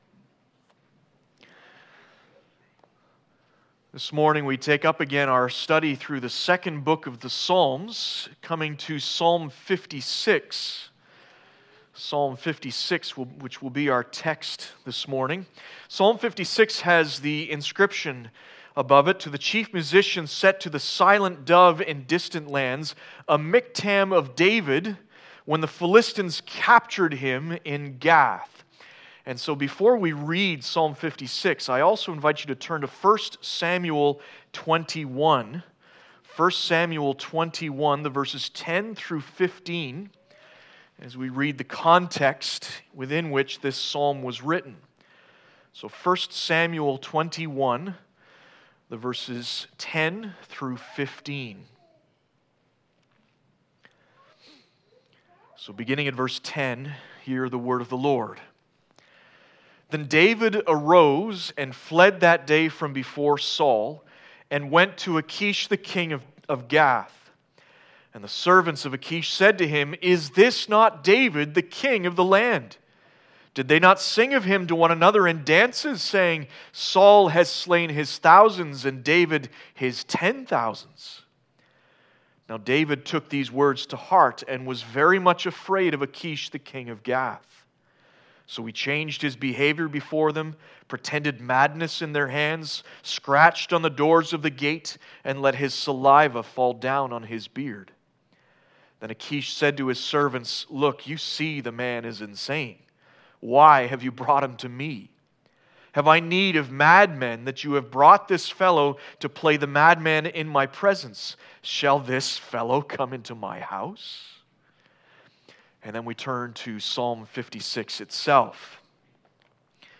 Psalm 56 Service Type: Sunday Morning Bible Text